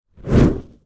Sound Effects
Fire Whoosh